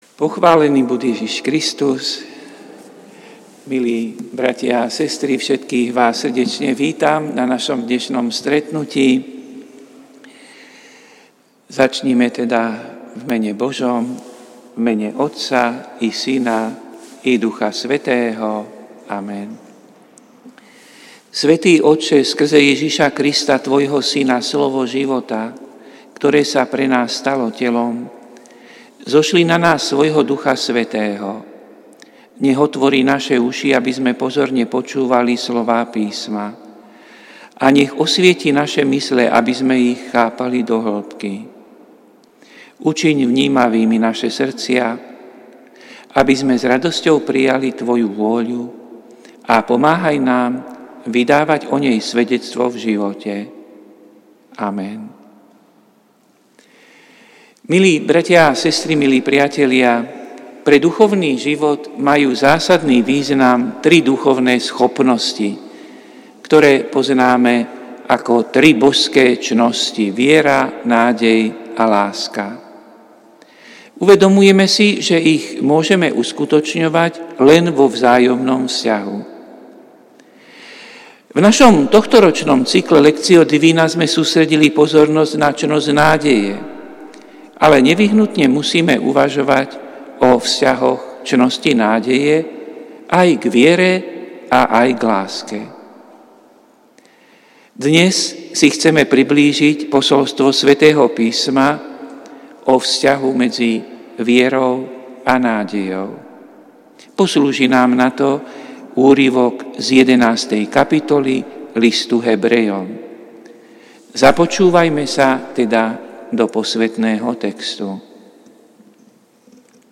Prinášame plný text a audio záznam z Lectio divina, ktoré odznelo v Katedrále sv. Martina 13. novembra 2024.